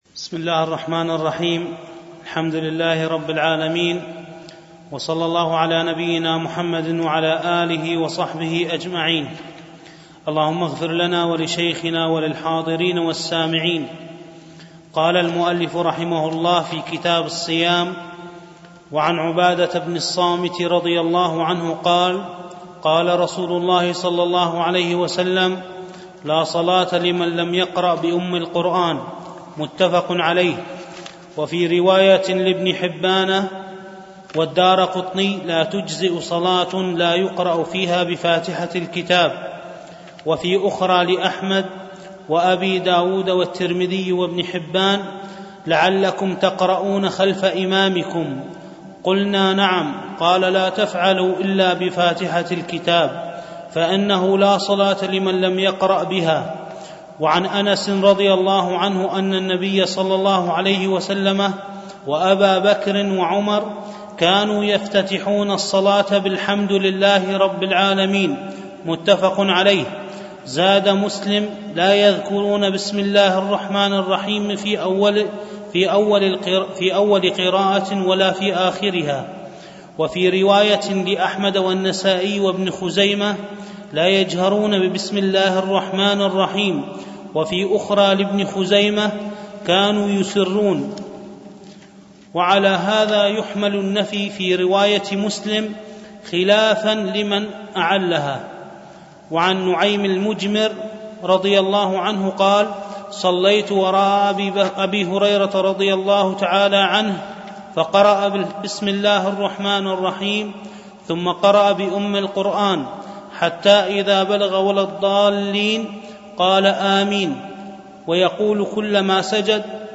الألبوم: دروس مسجد عائشة (برعاية مركز رياض الصالحين ـ بدبي)
التنسيق: MP3 Mono 22kHz 32Kbps (CBR)